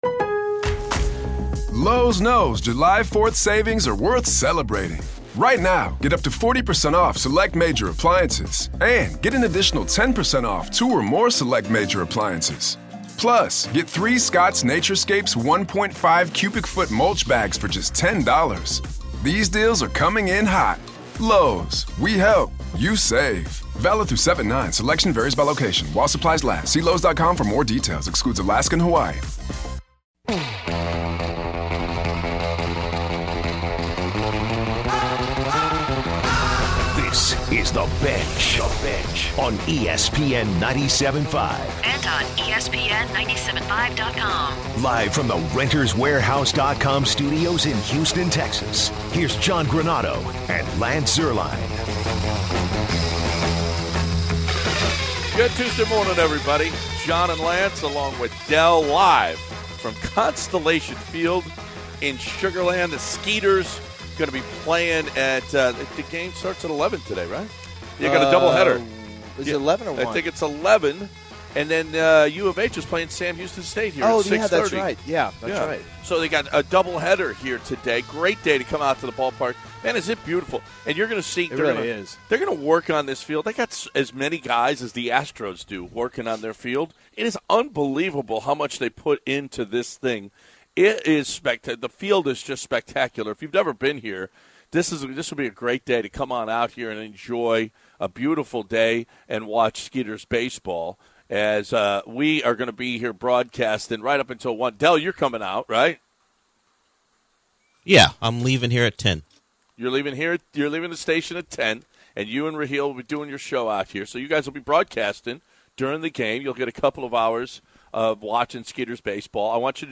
Utah jazz fan calls in to insult the guys and many callers end the hour by responding to angry Jazz fan caller.